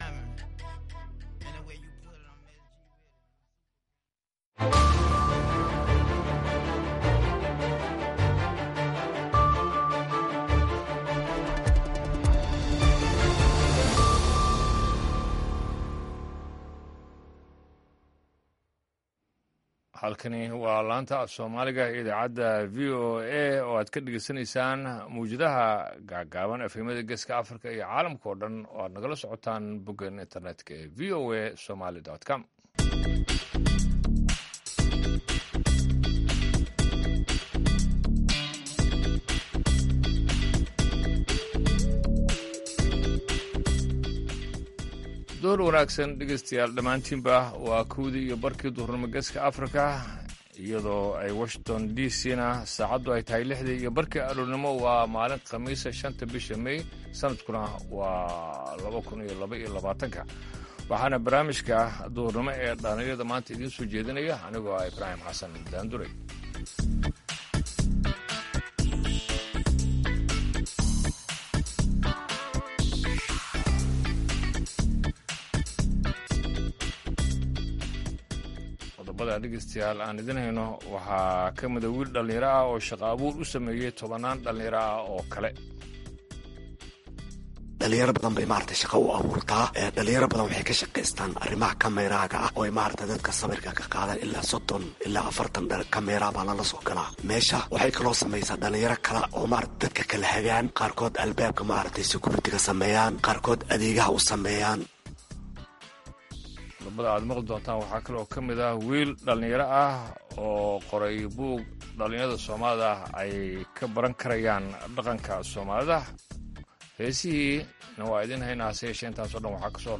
Idaacadda Duhurnimo waxaad qeybta hore ku maqli kartaa wararka ugu waaweyn ee Soomaaliya iyo Caalamka. Qeybta danbe ee idaacaddu waxay idiin soo gudbinaysaa barnaamijyo ku saabsan dhalinyarada maanta.